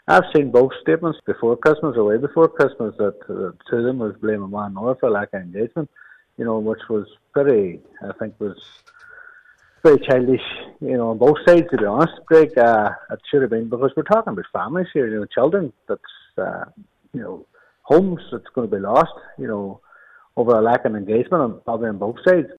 Speaking earlier on today’s Nine til Noon Show, Councillor Thomas Sean Devine says it must be remembered that at the core of this are eight families facing homelessness: